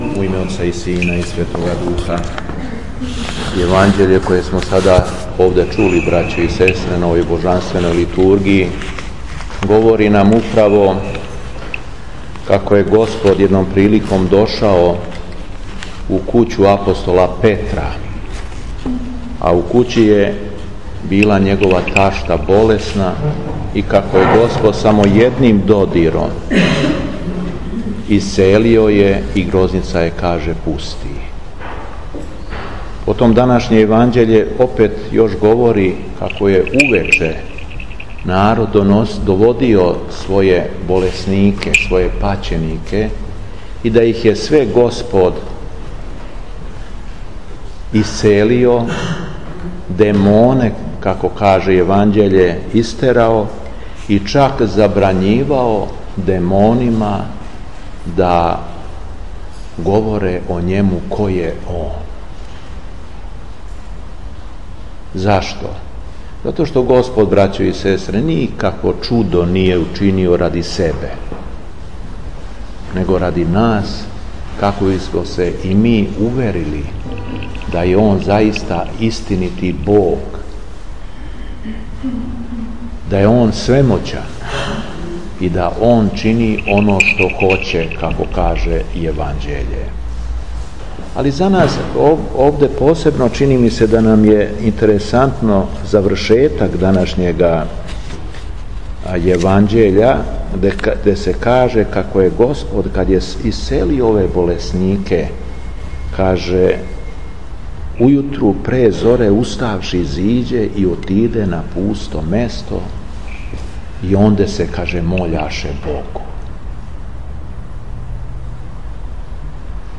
СВЕТА АРХИЈЕРЕЈСКА ЛИТУРГИЈА У СТАРОЈ ЦРКВИ У КРАГУЈЕВЦУ - Епархија Шумадијска
Беседа Епископа шумадијског Г. Јована